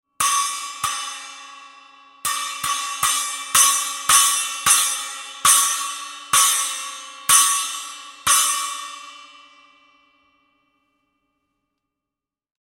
6″ Armor Holey Splash Cymbals (Approx 85 grams):
6__Armor_Holey-Splash.mp3